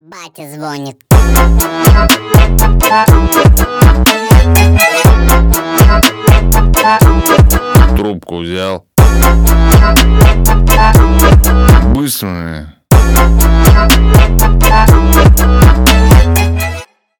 на русском на папу громкие с басами